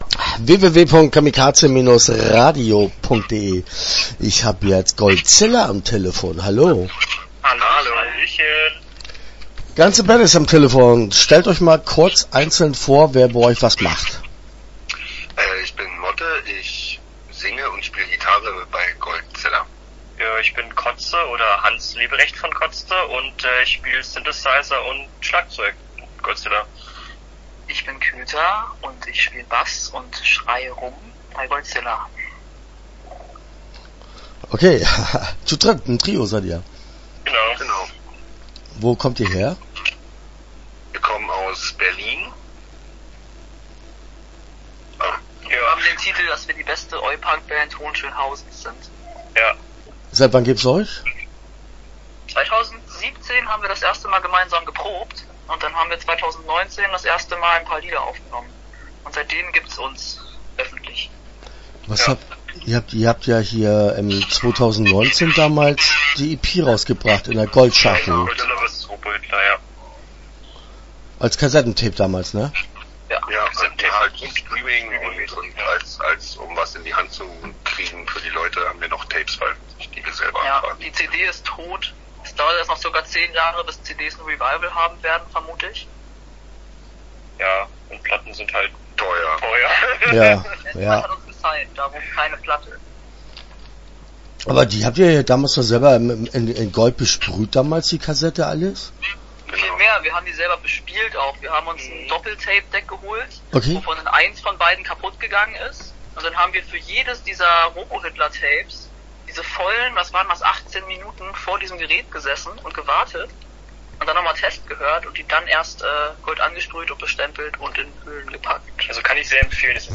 GOLDZILLA - Interview Teil 1 (12:24)